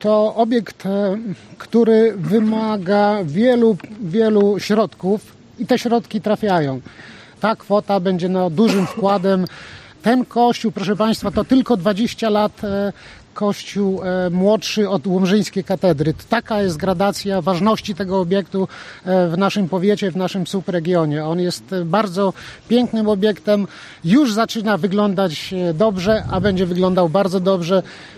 Dziś w Szczepankowie nastąpiło rozstrzygnięcie konkursów w ramach ochrony zabytków w powiecie łomżyńskim.
Kościół w Szczepankowie to wartościowy zabytek podsumowuje wicemarszałek województwa podlaskiego, Marek Olbryś: